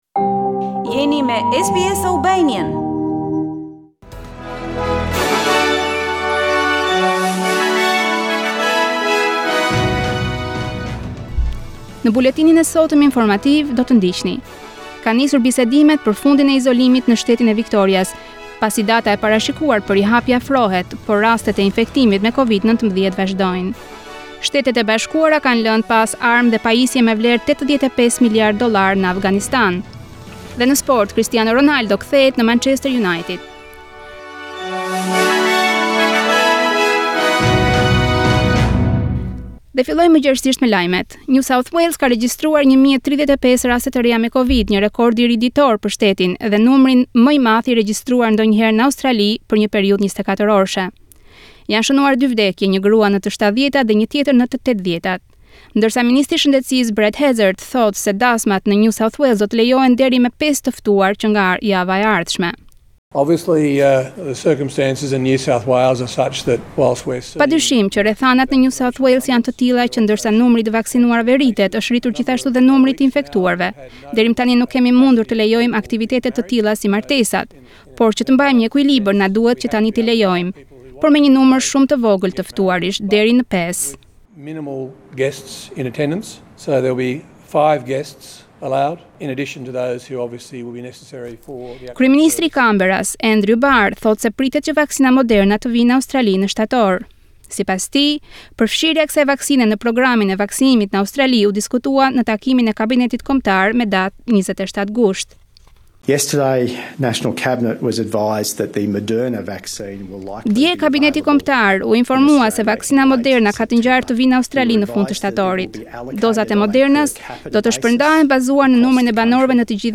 SBS News Bulletin in Albanian - 28 August 2021